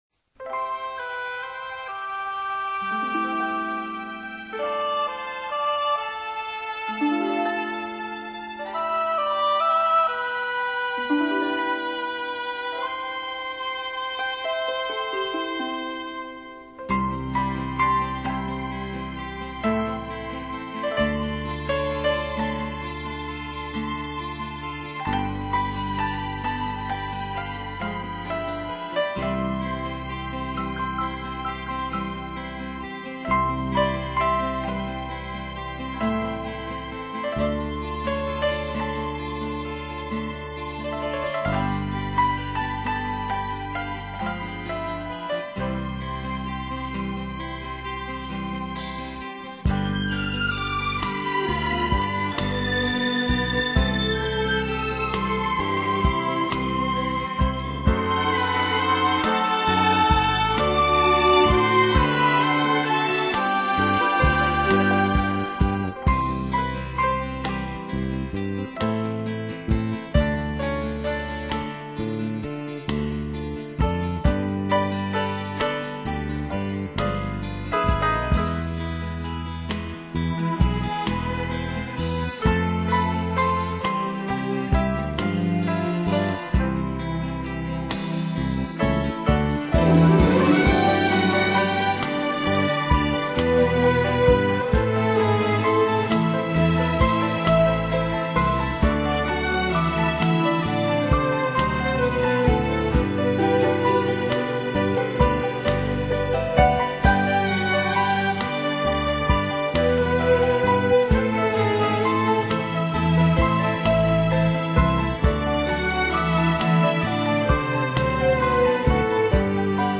减压放松经典的轻音乐，绝对松弛你的每一根神经